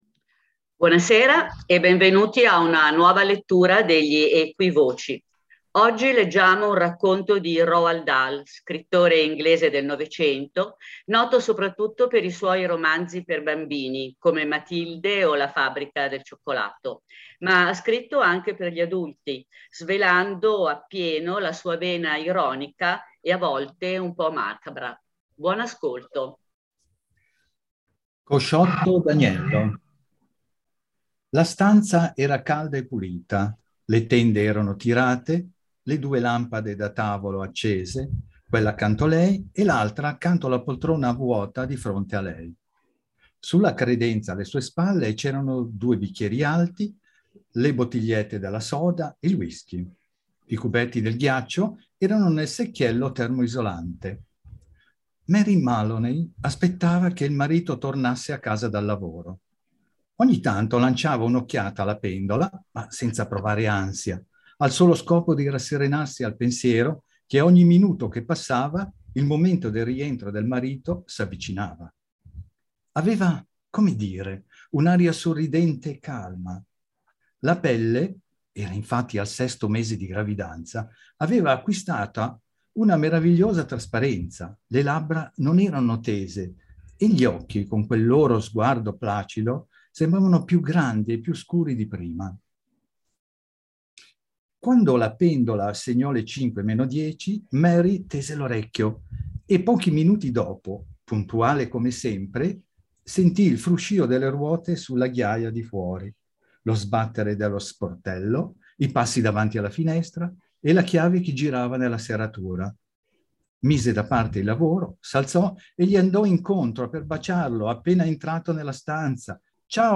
Elenco delle registrazioni delle letture "30 x 15"